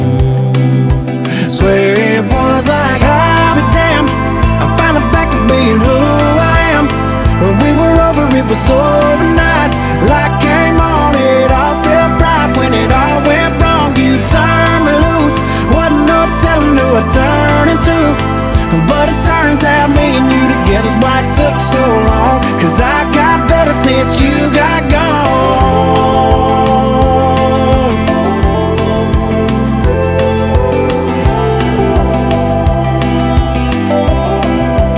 2025-05-16 Жанр: Кантри Длительность